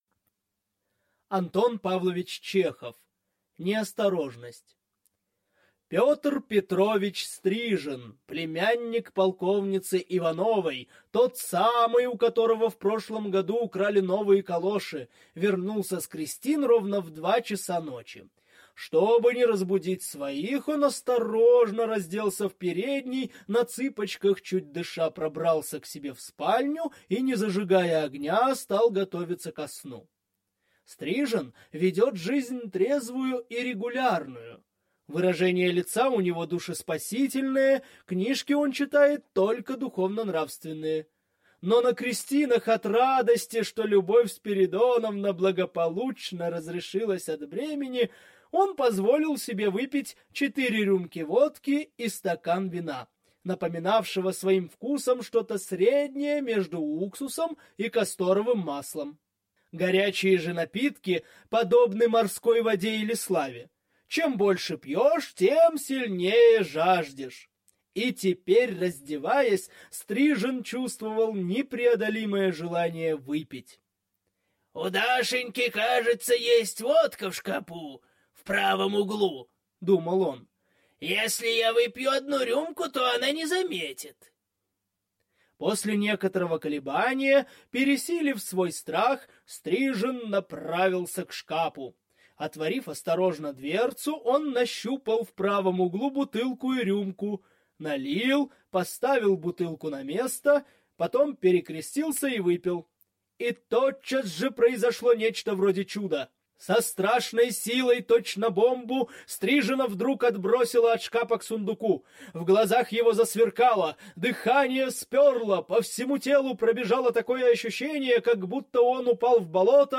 Аудиокнига Неосторожность | Библиотека аудиокниг